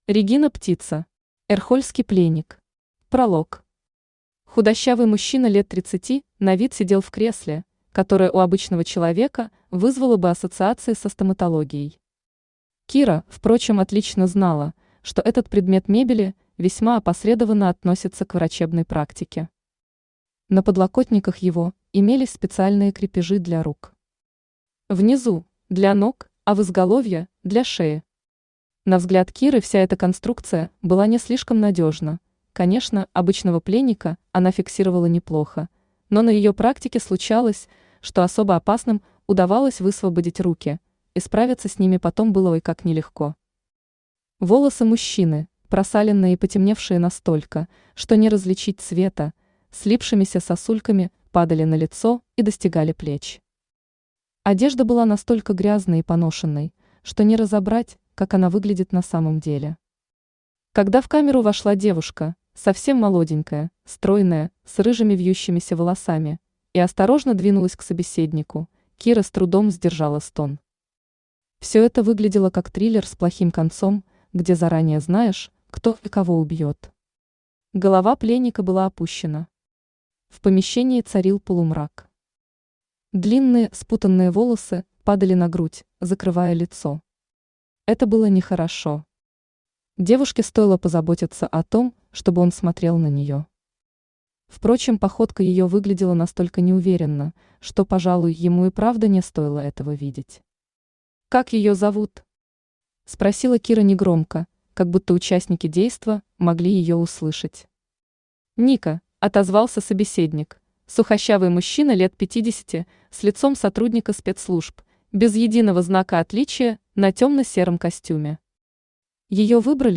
Аудиокнига Эрхольский пленник | Библиотека аудиокниг
Aудиокнига Эрхольский пленник Автор Регина Птица Читает аудиокнигу Авточтец ЛитРес.